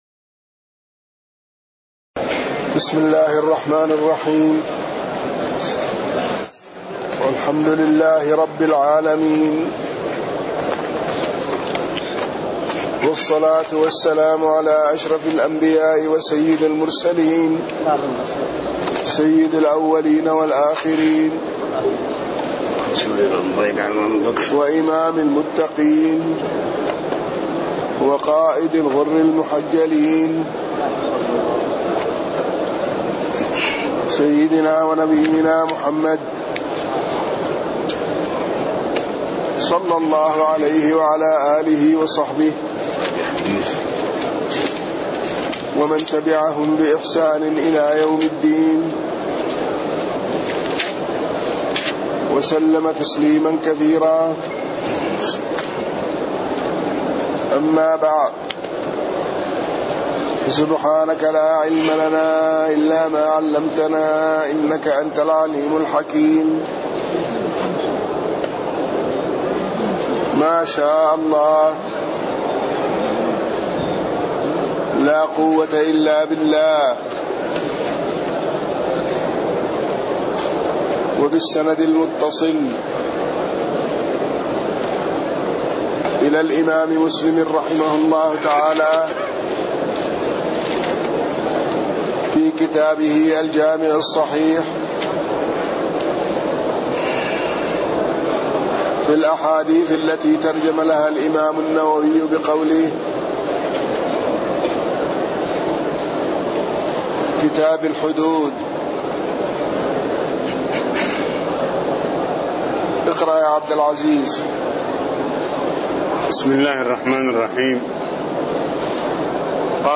تاريخ النشر ٢٠ جمادى الآخرة ١٤٣٥ هـ المكان: المسجد النبوي الشيخ